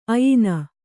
♪ ayina